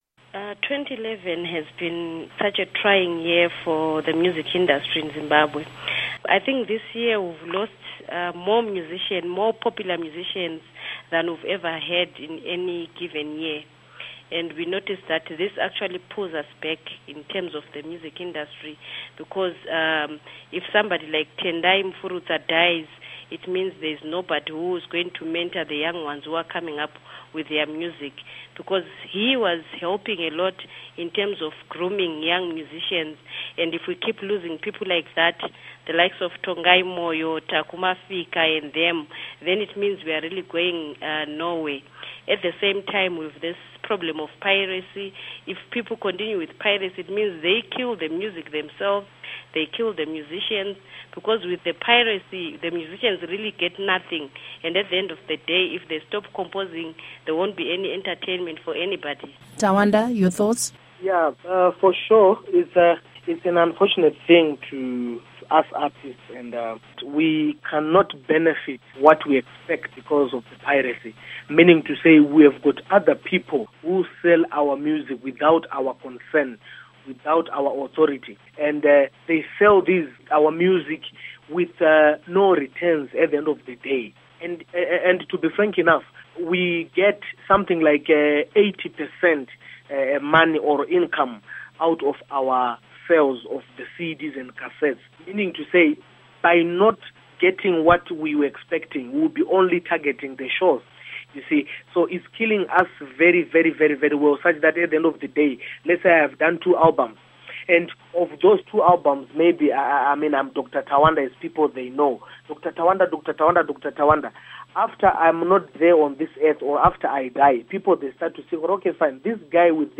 Music Panel Discussion